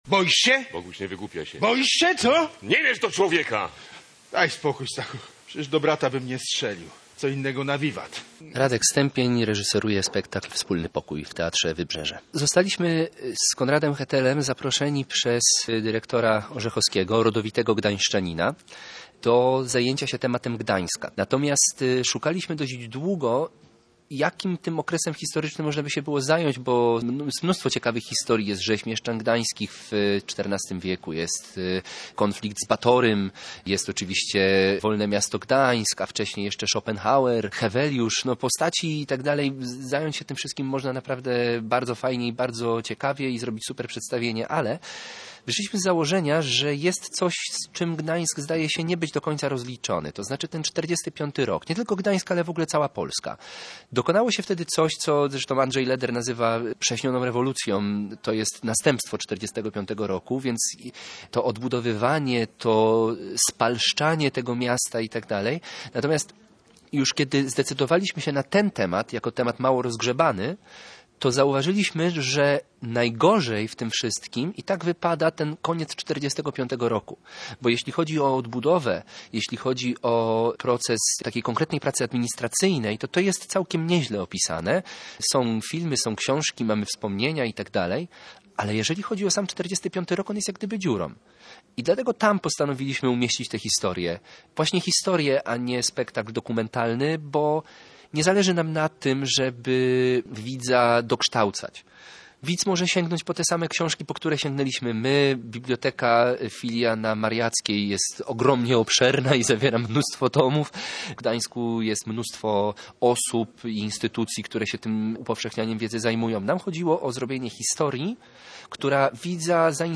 z próby medialnej: